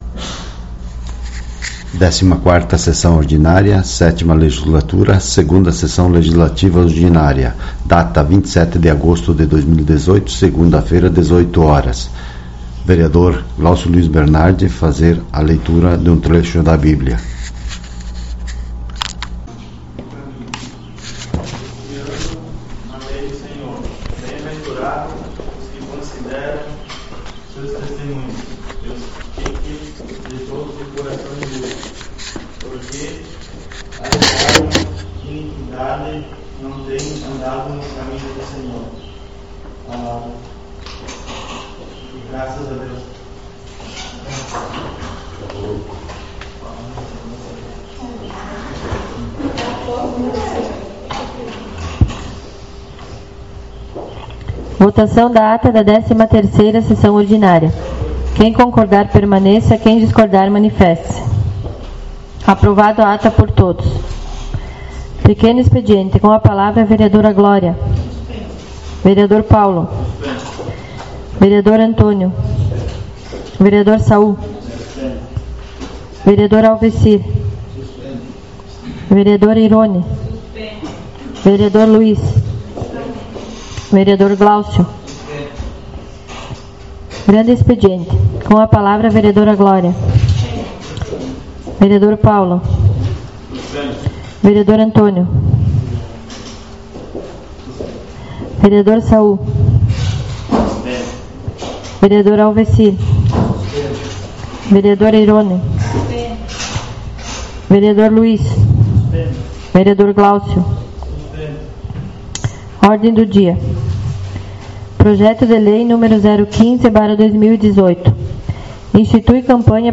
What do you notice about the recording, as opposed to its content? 14ª Sessão Ordinária 27.08.18 — Câmara Municipal